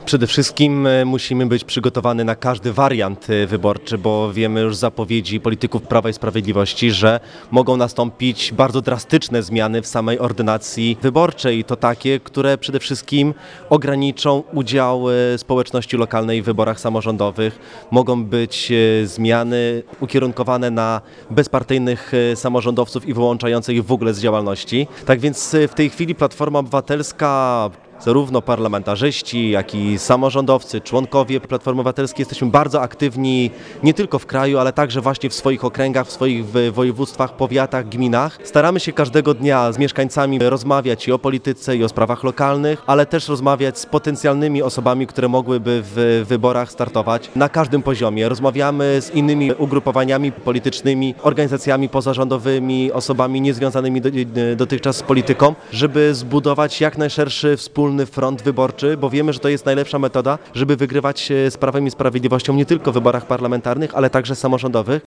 Po raz drugi w Ełku odbyło się spotkanie Klubu Obywatelskiego.
– Musimy te wybory po prostu wygrać- mówi Arkadiusz Myrcha poseł PO, który uczestniczył w spotkaniu Klubu Obywatelskiego.